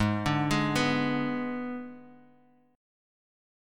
G#dim chord